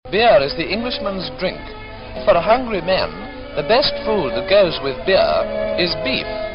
This was a vowel which glided from the lax quality ɪ to the quality ə within a single syllable. It can be heard in this clip from a 1930s Pathé documentary about beef and beer: